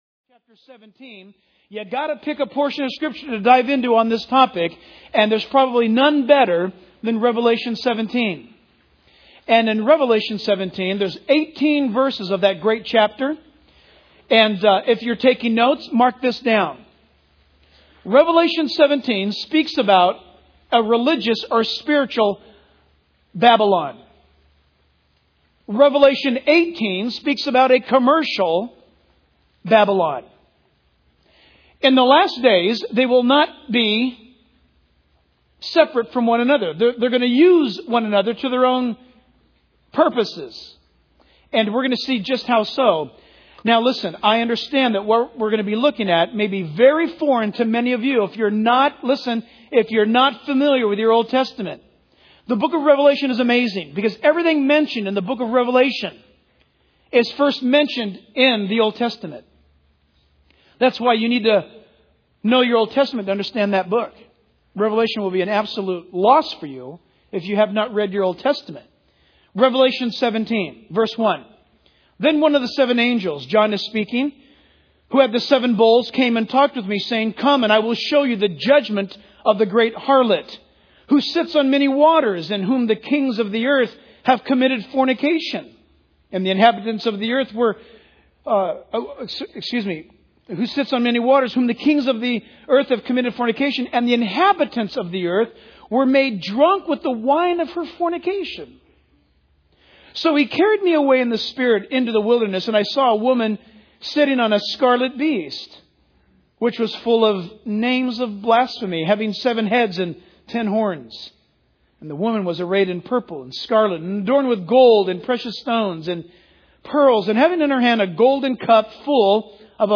In this sermon, the speaker begins by emphasizing the importance of understanding the concept of Babylon. He suggests that Babylon can refer to various things, such as the ancient city in Iraq or a religious and spiritual system. The speaker also mentions the rise of a dominant European market and speculates about the potential arrival of the Antichrist.